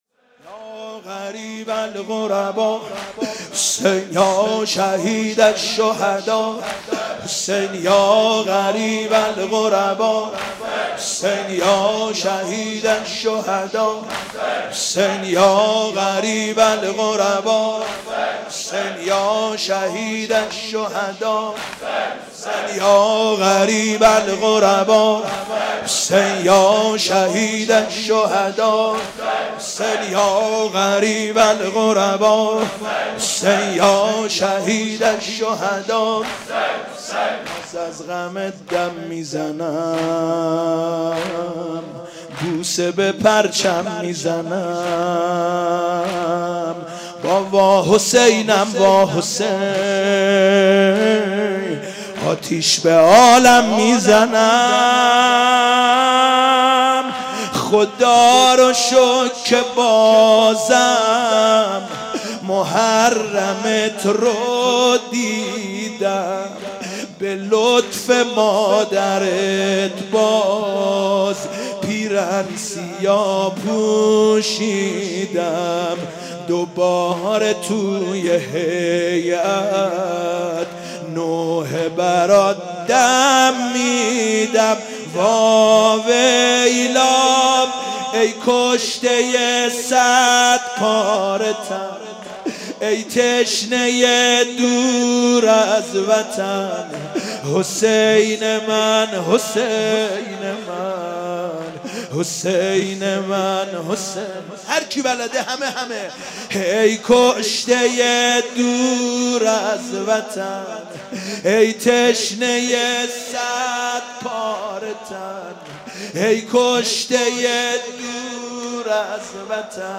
شب اول محرم97 - مسجد امیر - زمینه - من از غمت دم میینم بوسه به پرچم میزنم